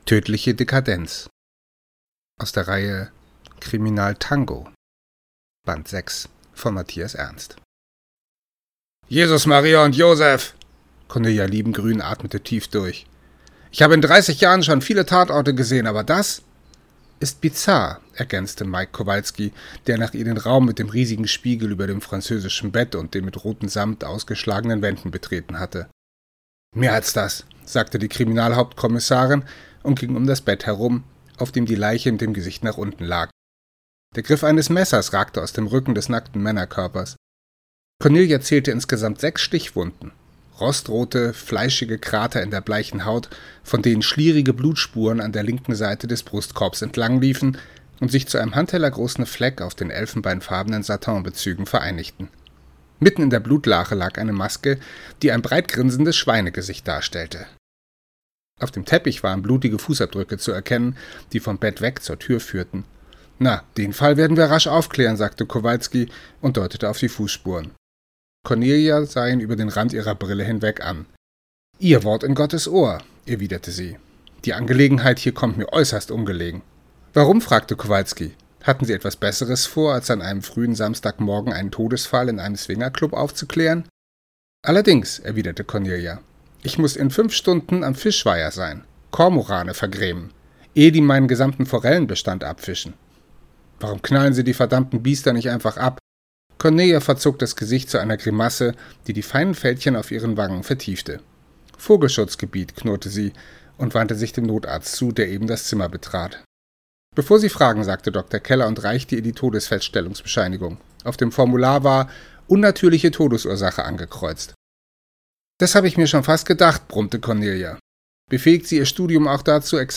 Leseprobe als Hörprobe